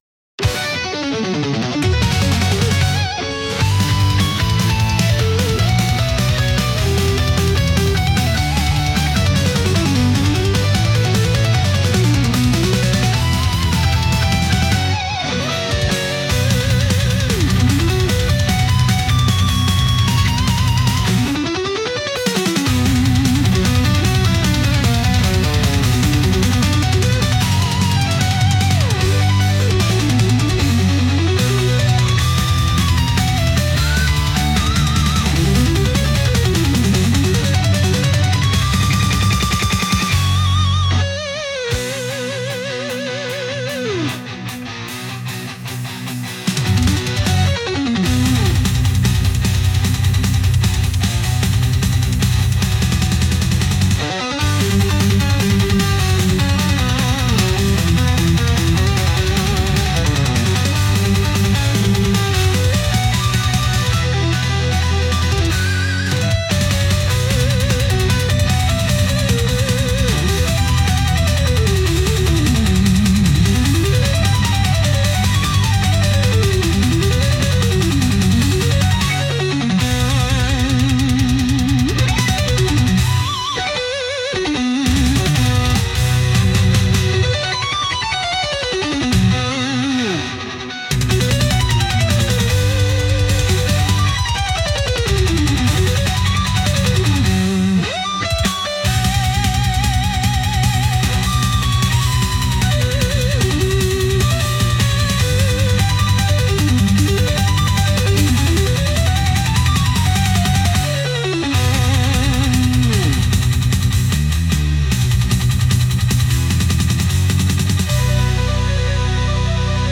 序盤だけ似ててそれからどんどん離れていくボスバトルっぽい曲です。